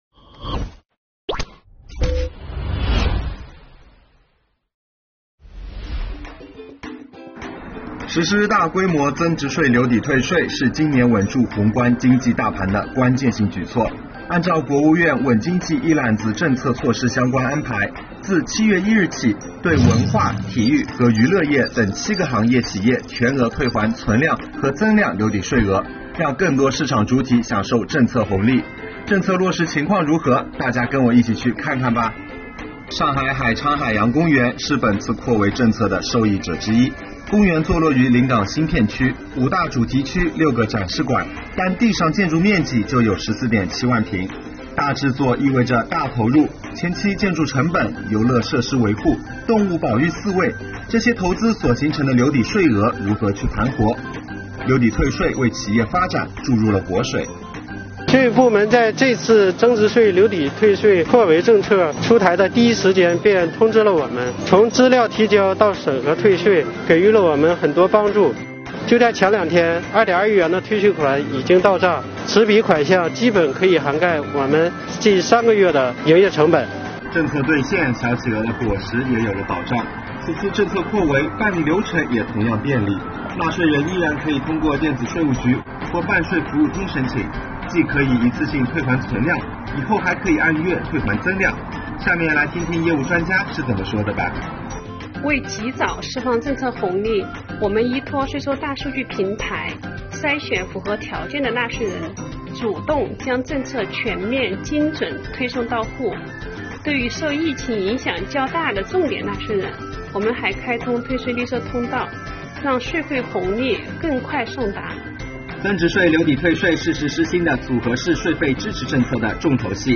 7月起，增值税留抵退税政策再次扩围，继制造业等六大行业后，文化娱乐、住宿餐饮等受疫情影响较大、与民生息息相关的七大行业也被纳入其中。上海海昌海洋公园是本次扩围政策的受益者之一，让我们跟着税务主播一起去听听企业是怎么说的吧……